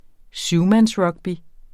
Udtale [ ˈsywmans- ]